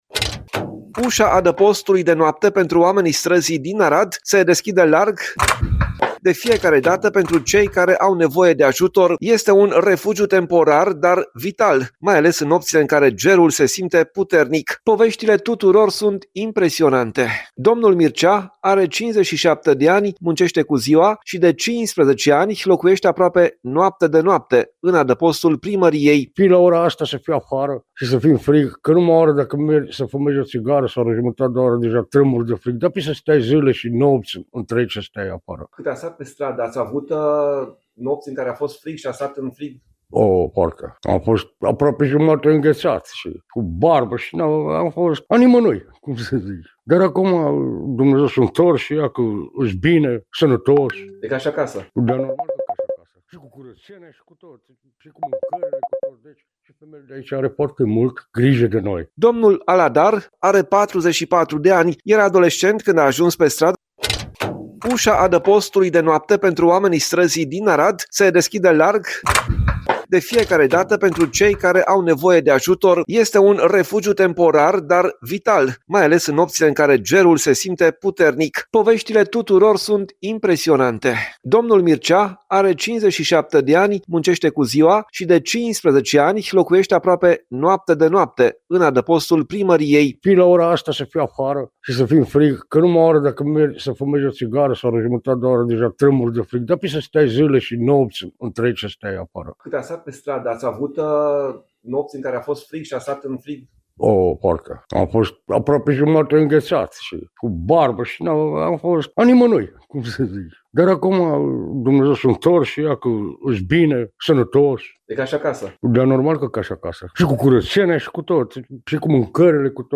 Reportaj: Adăpostul de noapte din Arad, un refugiu pentru persoanele fără locuință | GALERIE FOTO